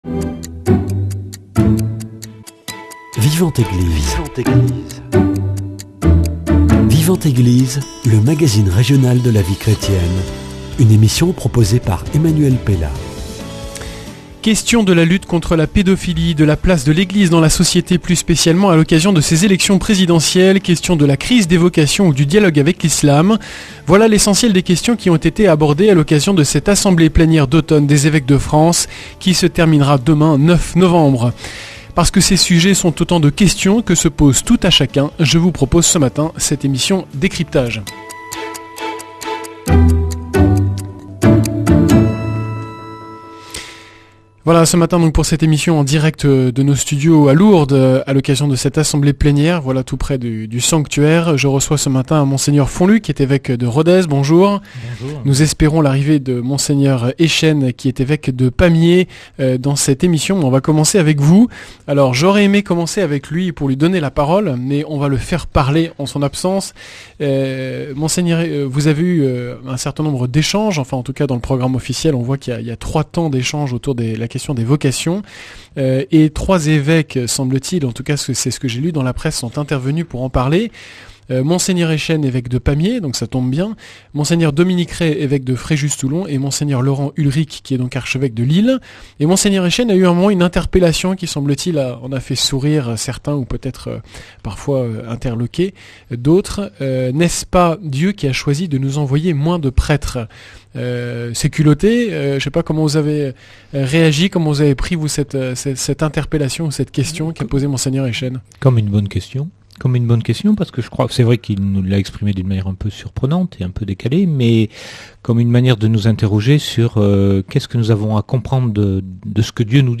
A l’occasion de l’assemblée des évêques de France à lourdes, Mgr François Fonlupt, évêque de Rodez et Mgr Jean-Marc Eychenne, évêque de Pamiers, reviennent sur trois grands sujets à l’ordre du jour : la question des vocations de prêtres, l’élection présidentielle à venir et le dialogue avec les musulmans.
En direct de Lourdes pour l’assemblée plénières des évêques de France